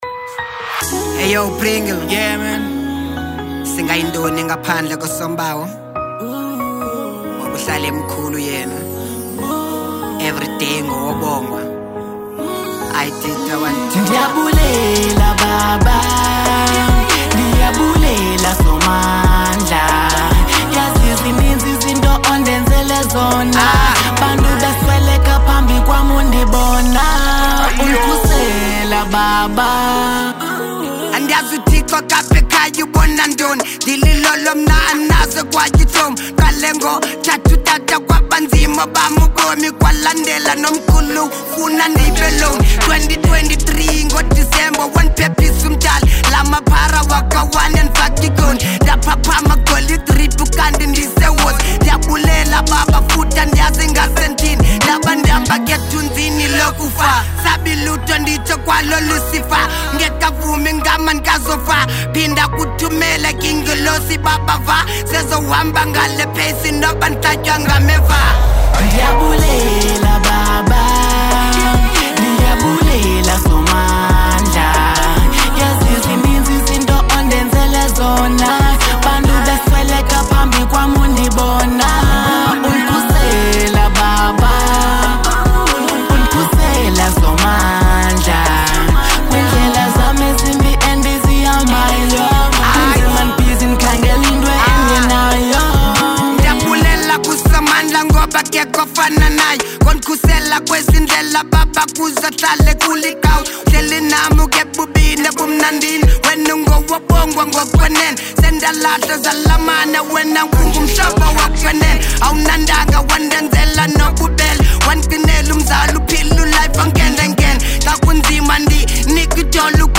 Genre : Venrap
The song is so emotional and very dope